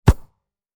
Soccer Ball Hit Sound Effect
Description: Soccer ball hit sound effect. This soccer ball hit sound effect captures a realistic football being kicked with a clear, natural impact.
Soccer-ball-hit-sound-effect.mp3